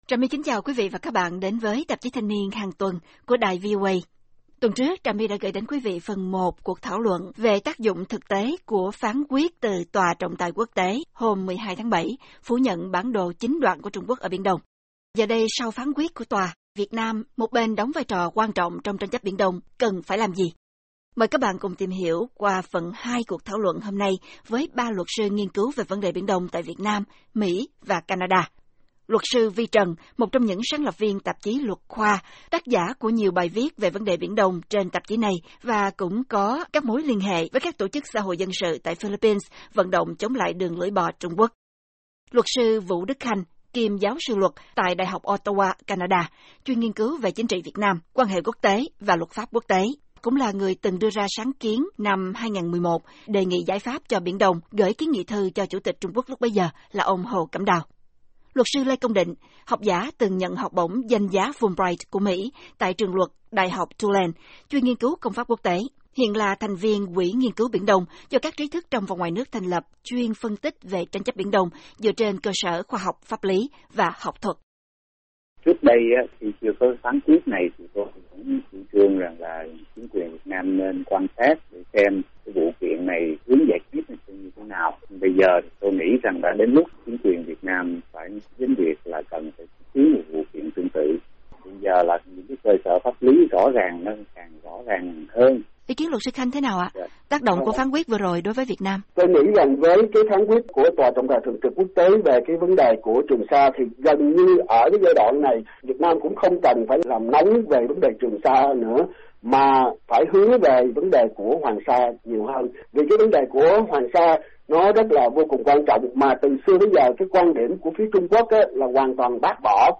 Tạp chí Thanh Niên VOA hôm nay mời các bạn cùng tìm hiểu qua phần hai cuộc thảo luận với 3 luật sư nghiên cứu về vấn đề Biển Đông tại Việt Nam, Mỹ, và Canada